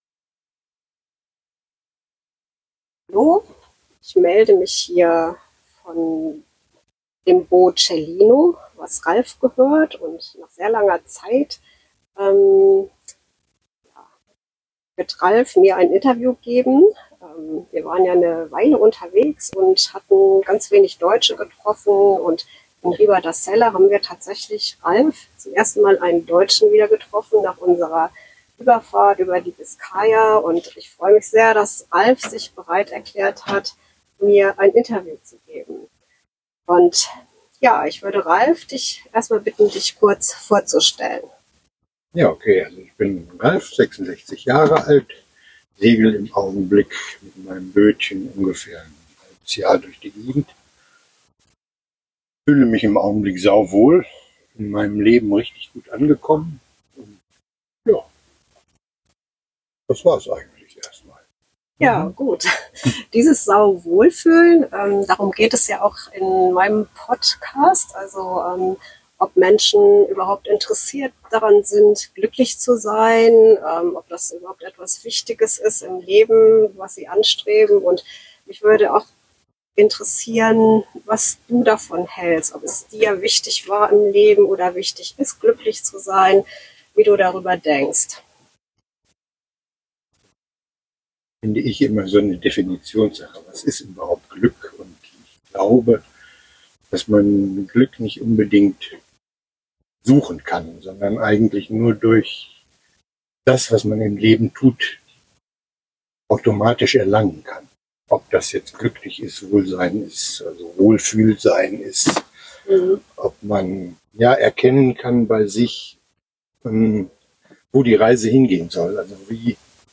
Interviewreihe zum Thema Glück